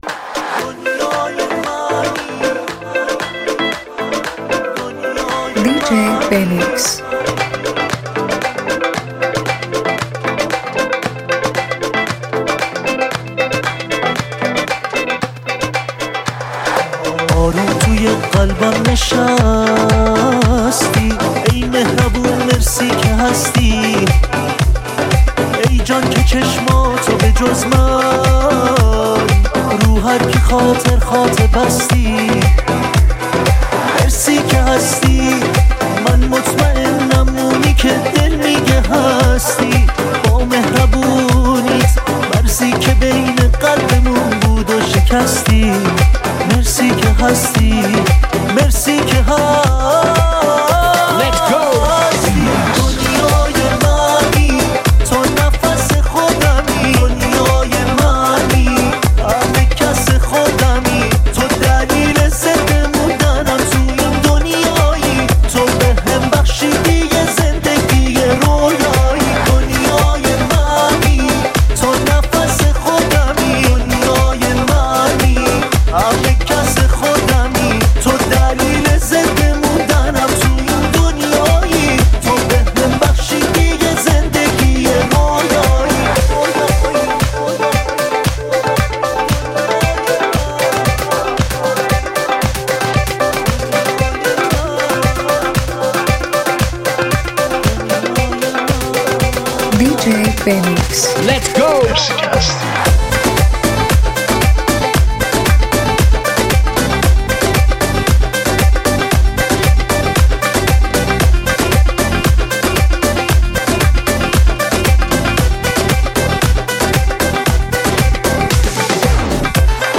ریمیکس جدید و شاد